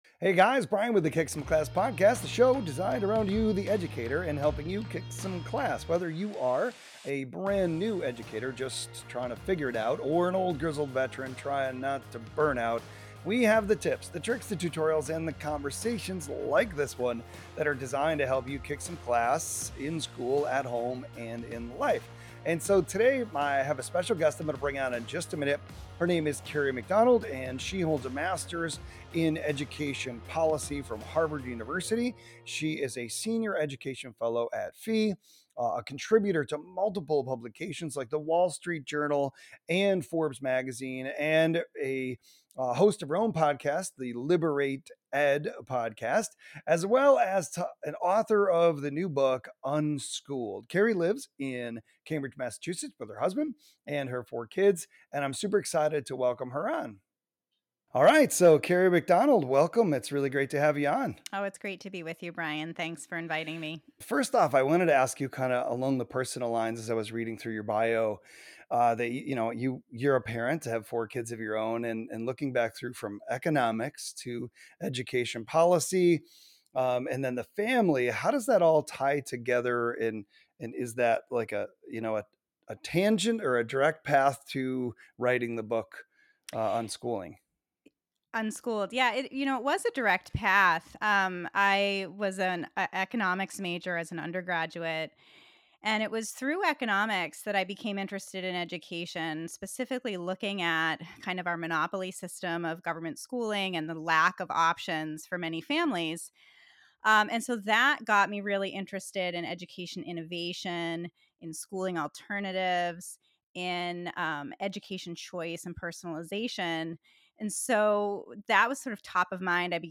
Join us for this unique conversation.